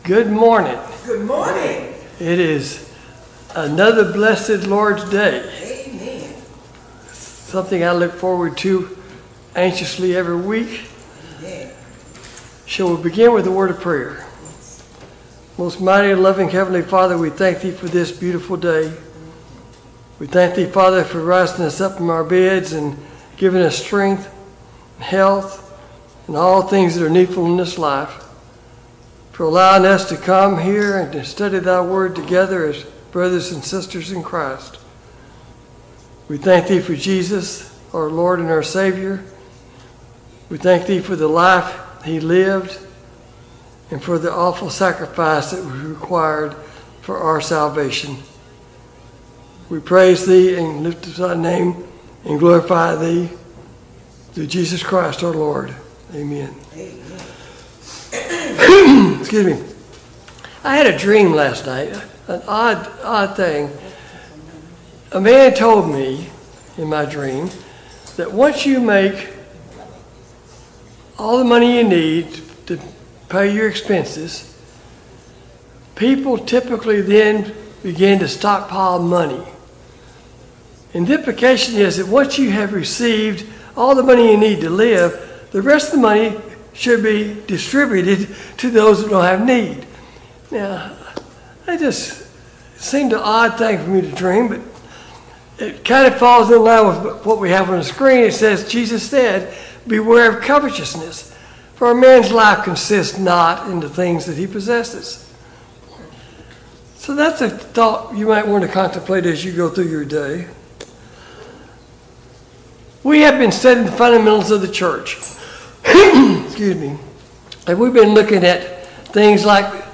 Service Type: Sunday Morning Bible Class Topics: The Early Church « 41.